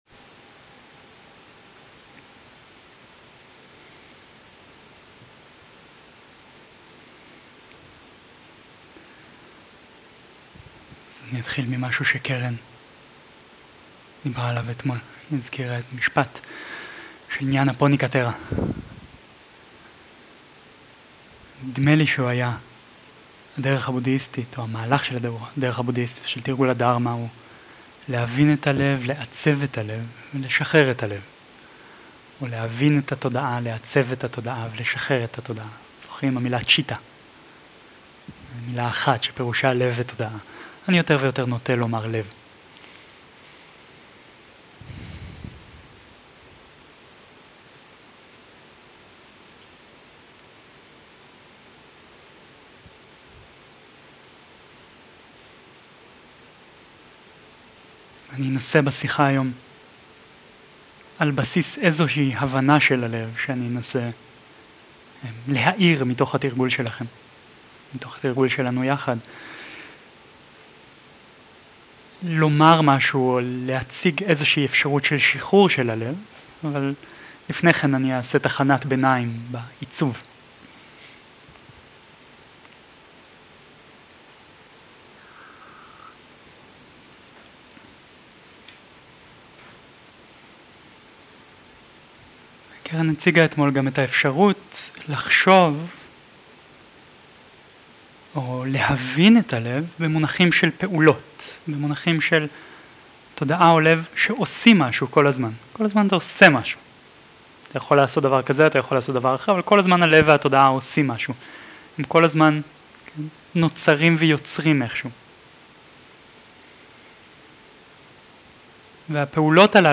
שיחת דהרמה
Dharma type: Dharma Talks שפת ההקלטה